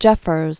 (jĕfərz), (John) Robinson 1887-1962.